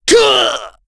Kasel-Vox_Damage_03.wav